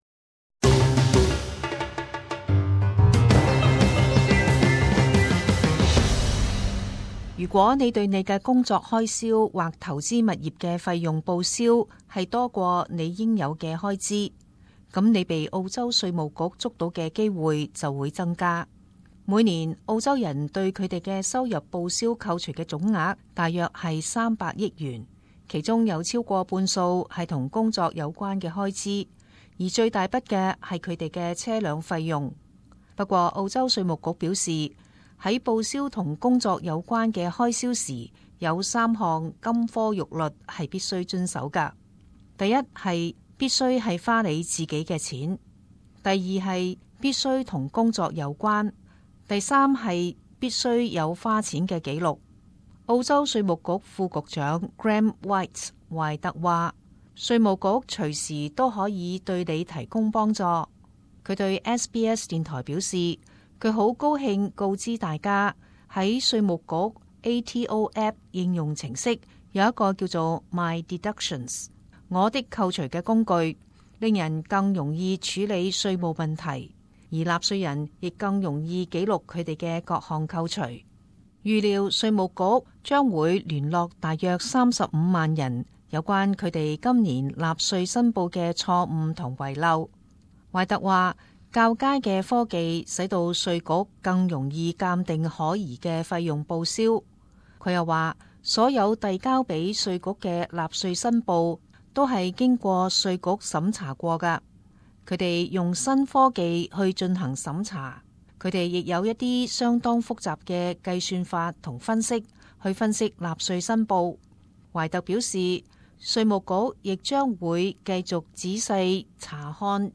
時事報導 - 澳洲稅局三項金科玉律